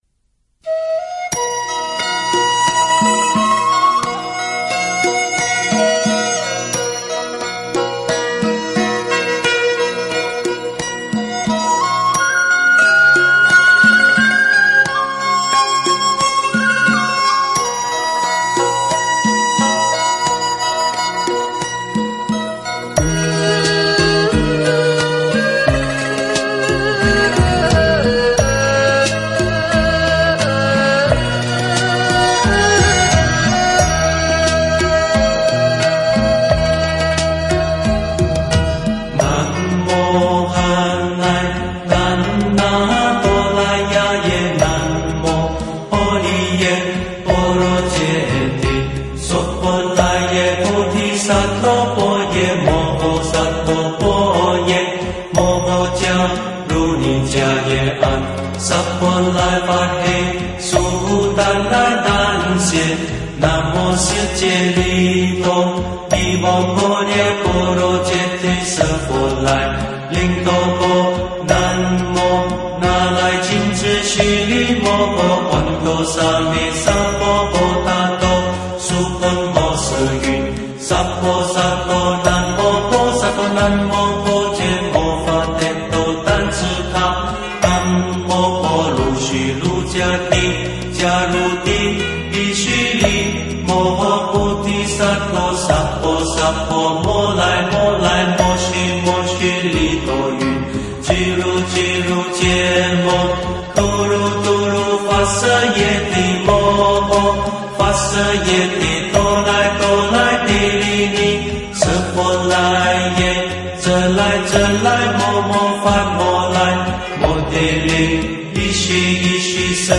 大悲咒完整唱诵，庄严流畅，适合静坐前后及日常听诵。